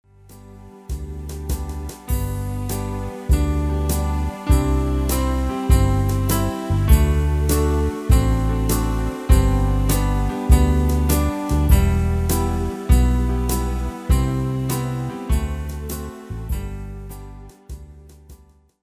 slovenské koledy v ľahkej úprave pre klavír